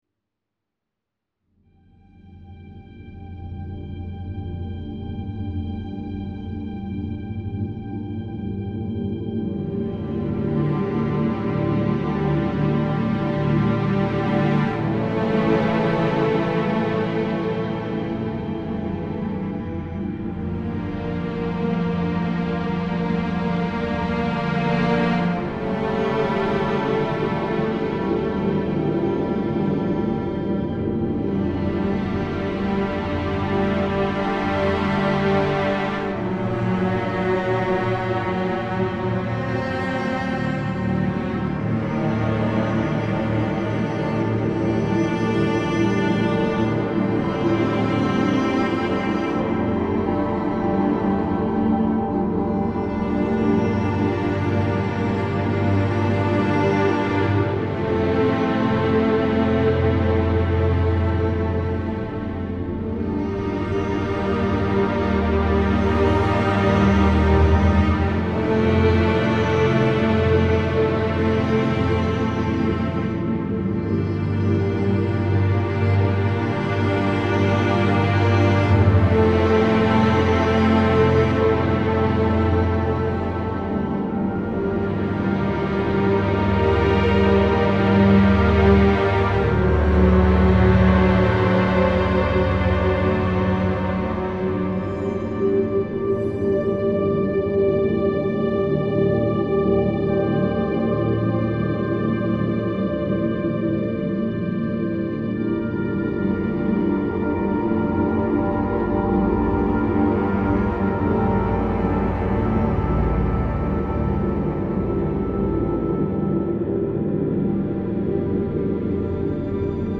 It's quite desolate in parts and super atmospheric.
This soundscape is so beautiful!